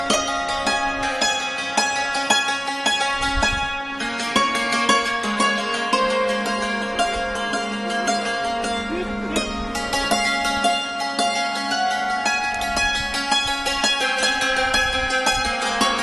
DIY Learn a Language - Ukrainian Musical Instruments
Cymbals
Cymbals.mp3